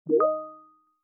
Classworks / public / sounds / Teams 默认.mp3